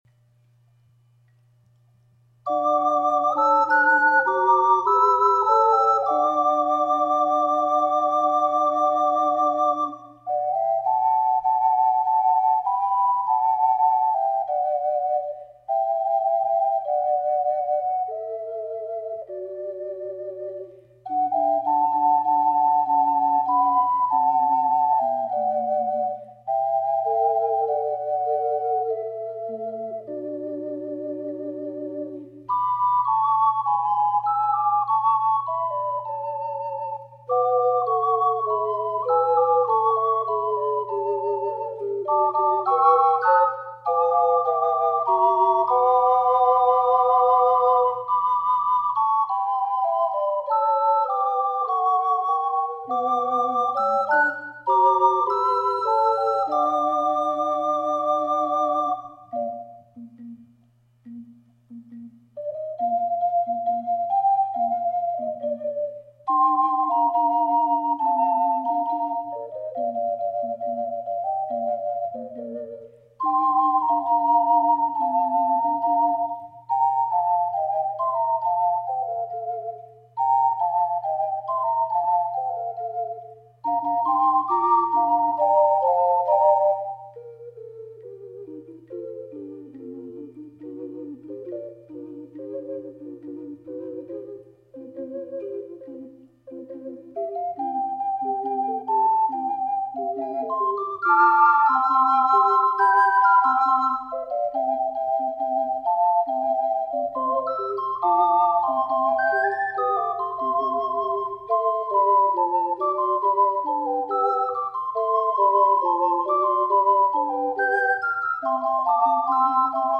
2ndはオクターブ下を吹きました。
最初のテンポは､82と指定されていますが、試奏では100で吹きました。